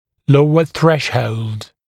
[‘ləuə ‘θreʃhəuld][‘лоуэ ‘срэшхоулд]более низкий порог, более низкие пороговые показания